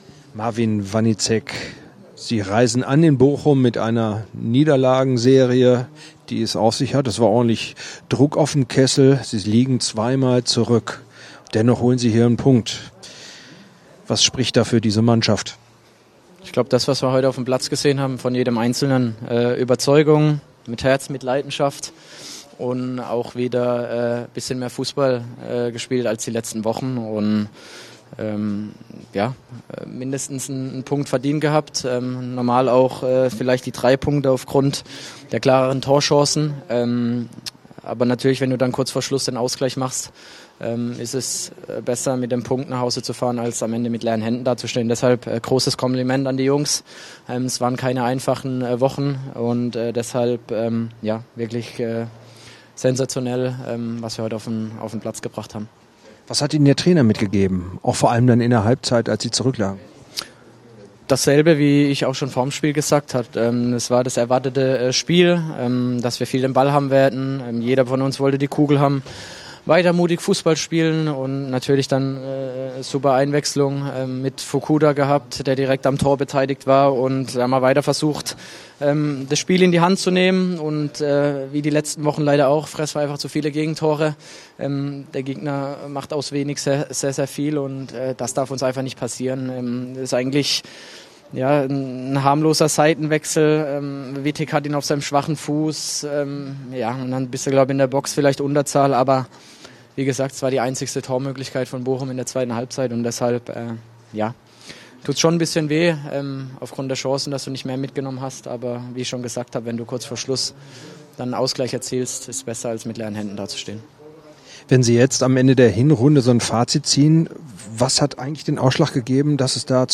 Nach 2:2 in Bochum: Marvin Wanitzek vom KSC im Interview - SWR Sport